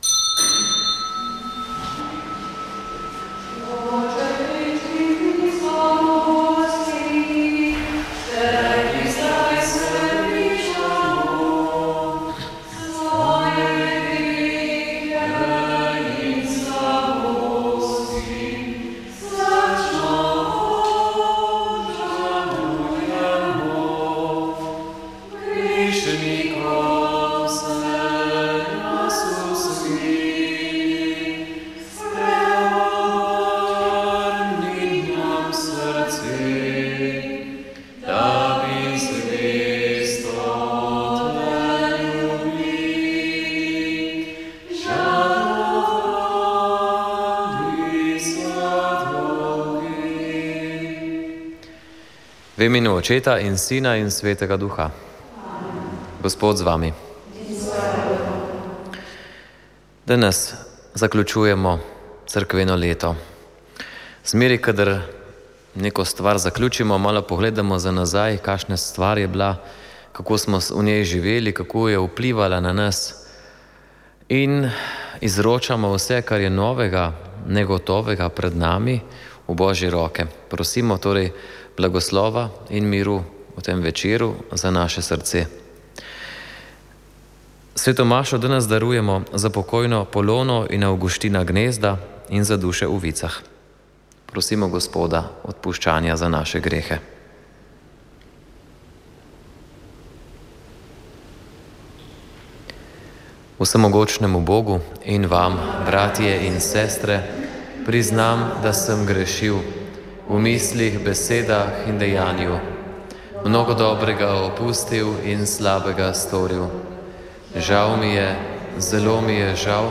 Sveta maša
Sv. maša iz župnijske cerkve sv. Jožefa in sv. Barbare iz Idrije 26. 11.
Sv. mašo je ob ljudskem petju